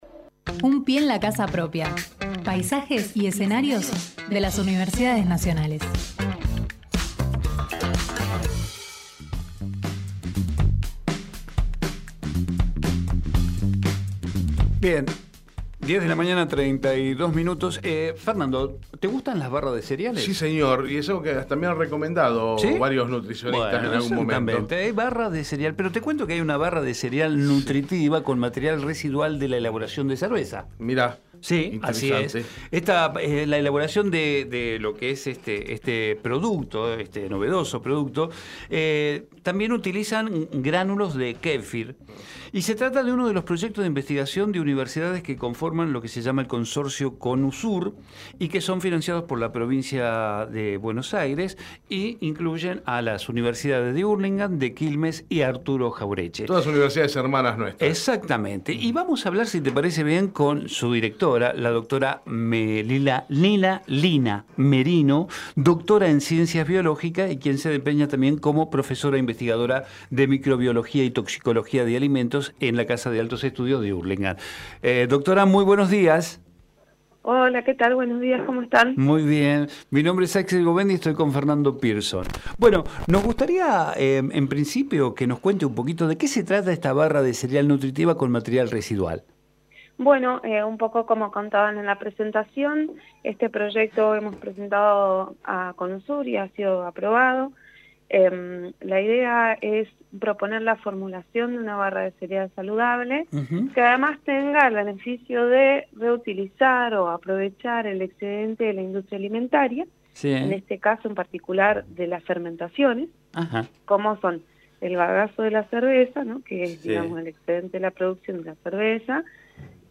Hacemos PyE Texto de la nota: Compartimos la entrevista